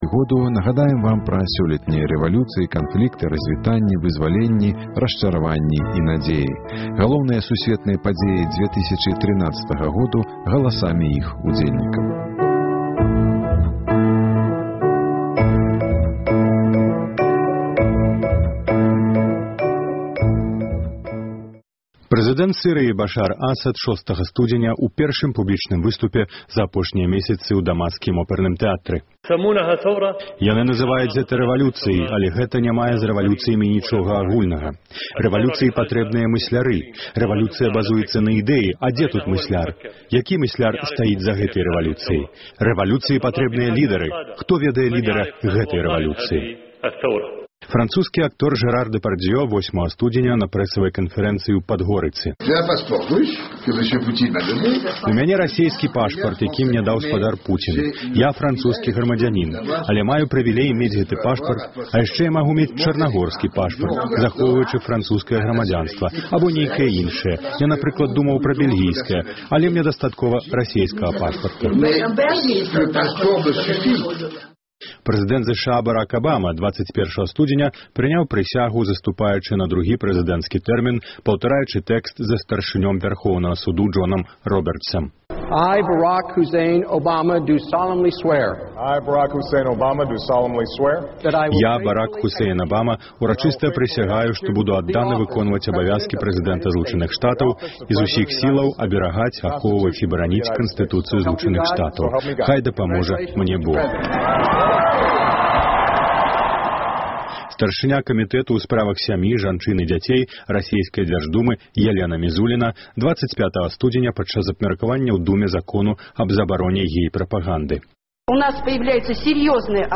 Мы нагадаем вам пра сёлетнія рэвалюцыі, канфлікты, разьвітаньні, вызваленьні, расчараваньні і надзеі. Галоўныя сусьветныя падзеі 2013 году галасамі іх удзельнікаў.